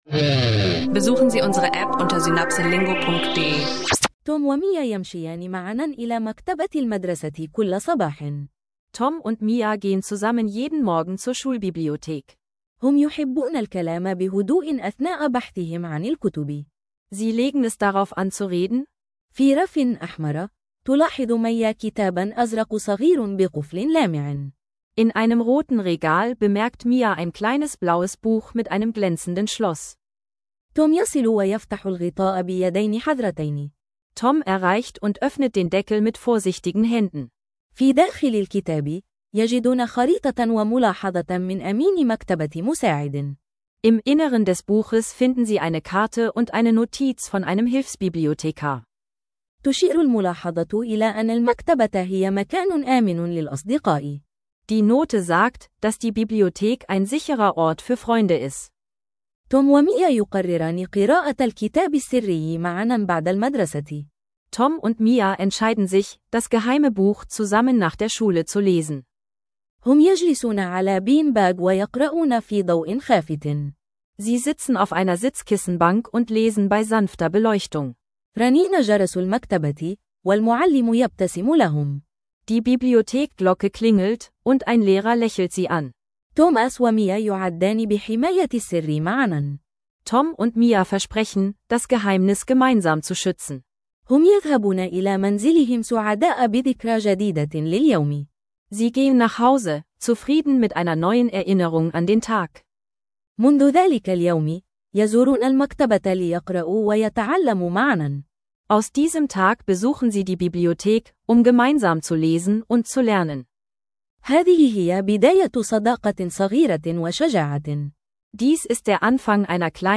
Zwei Geschichten: Arabisch lernen im Alltag durch einfache Dialoge über Freundschaft in der Bibliothek und ein geheimnisvoller Handschuh am Strand